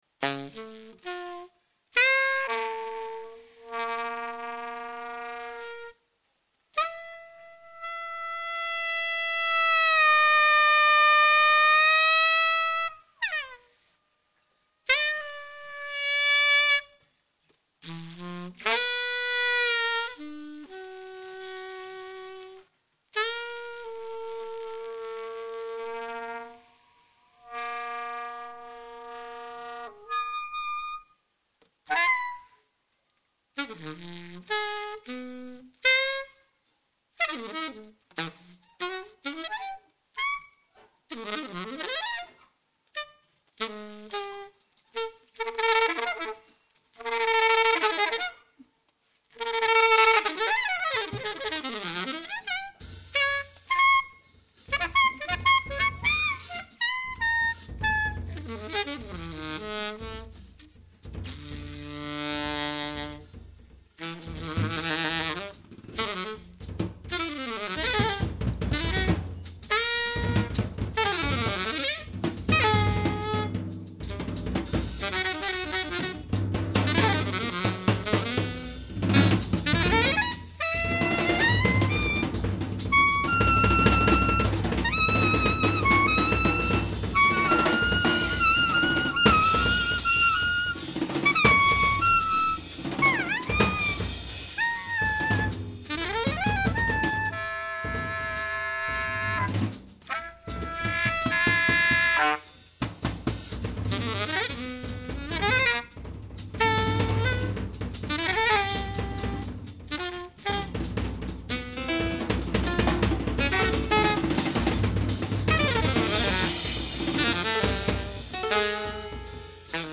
#3-4 recorded in concert at Jazz Club Fasching,